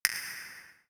Other Sound Effects
UI_033.wav